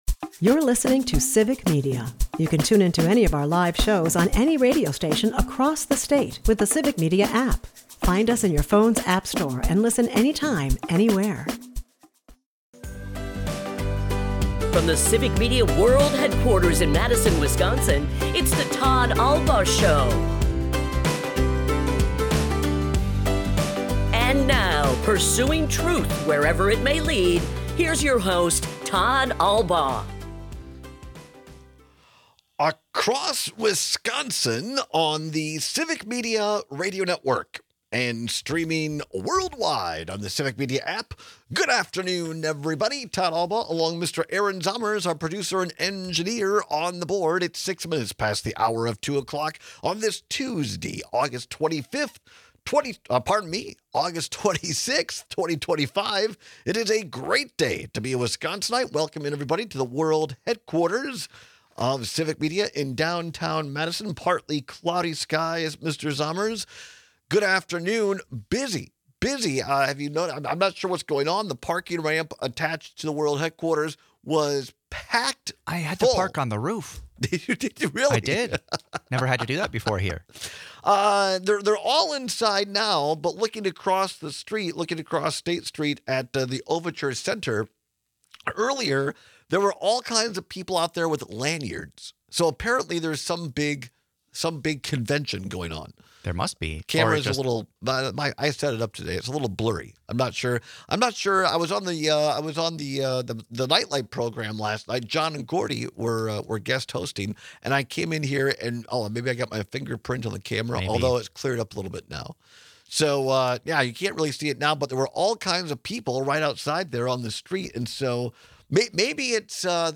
This year’s state budget failed to continue funding for the Knowles-Nelson Stewardship Program, which is one of our state’s most impactful conservation programs. At the bottom of the hour, we welcome two elected officials who are trying to fix this situation. State Sen. Jodi Habush Sinykin and Rep. Tip McGuire are joining other Democrats to propose a solution, but the Republicans have their own ideas.